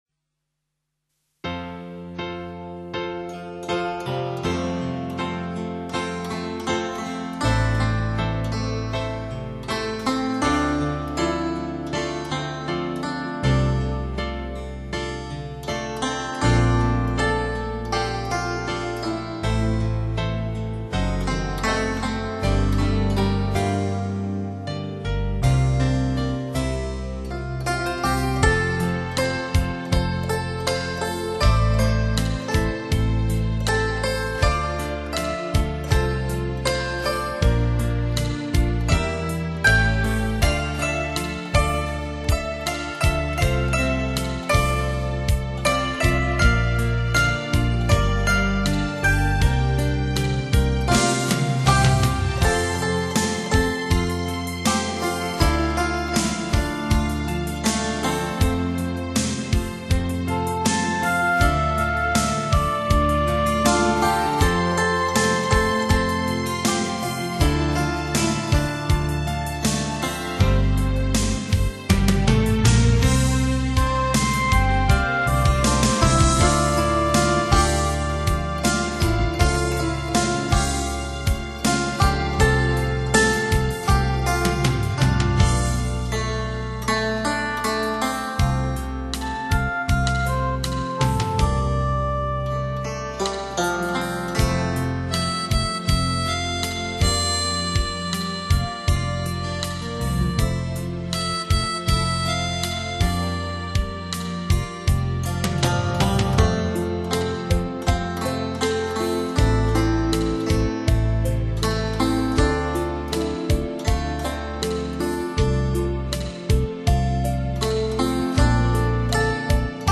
古筝，弹弦乐器。
特有动人心弦而又激昂震撼之音色，演译不朽经典旋律，予人万马千军感受...